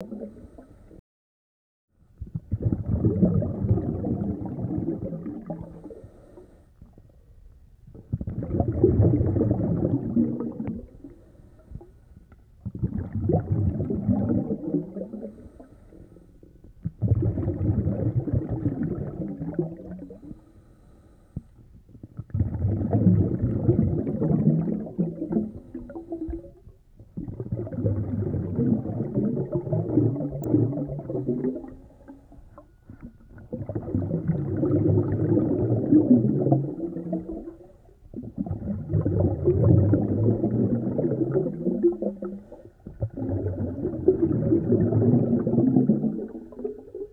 Index of /90_sSampleCDs/E-MU Producer Series Vol. 3 – Hollywood Sound Effects/Water/Scuba Breathing